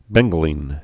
(bĕnggə-lēn)